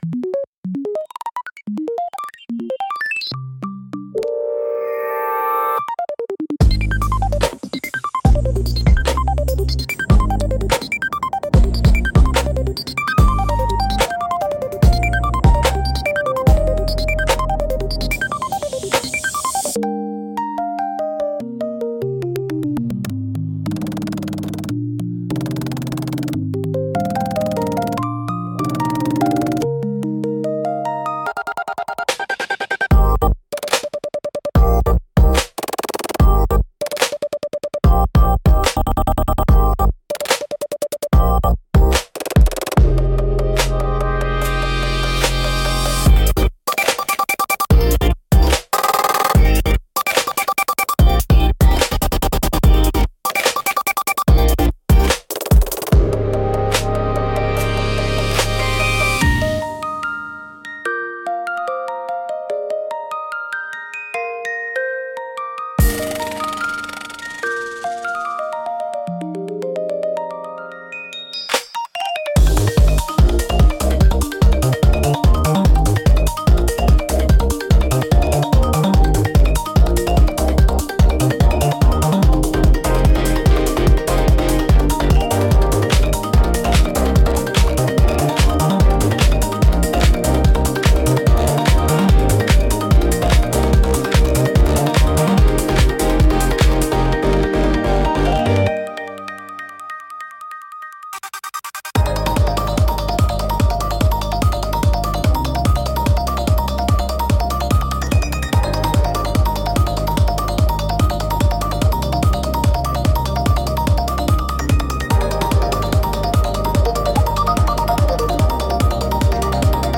聴く人の感覚を刺激し、緊張感や好奇心を喚起しながら、独自の雰囲気を強調する効果があります。